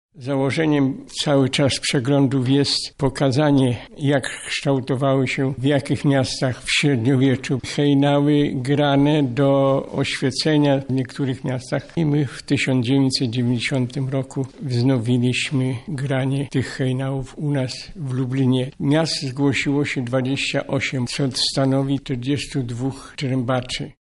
krzykacz miejski